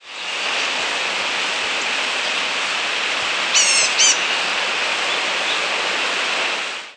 Blue-gray Gnatcatcher Polioptila caerulea
Flight call description A soft, mewing "bzew-bzew-bzew" (typically two to five notes).
Diurnal calling sequences: